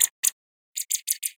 Chipmunk Sound
animal